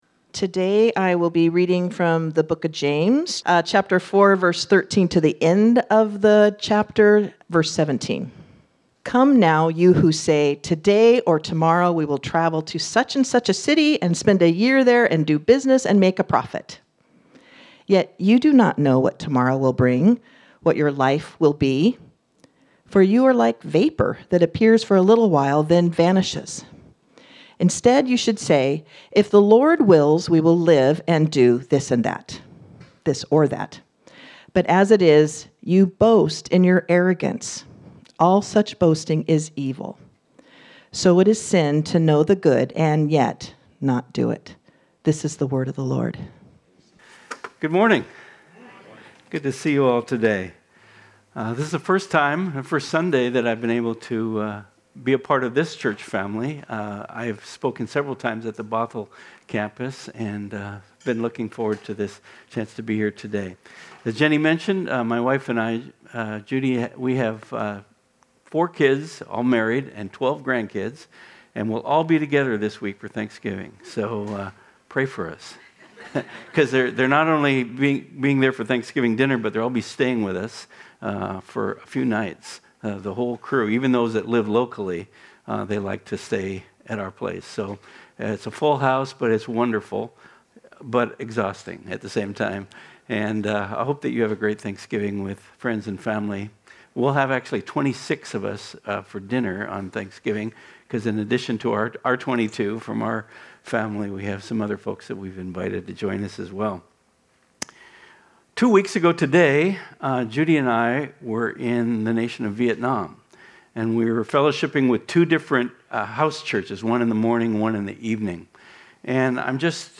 This sermon was originally preached on Sunday, November 23, 2025.